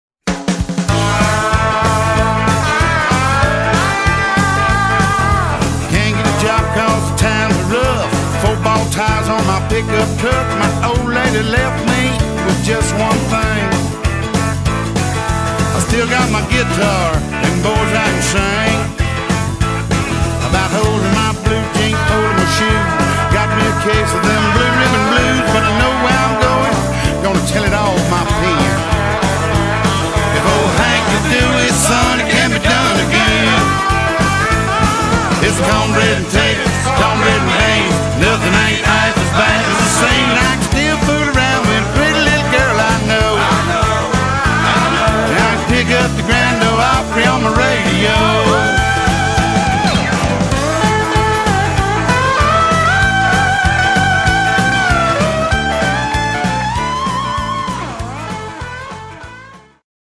percussion
guitar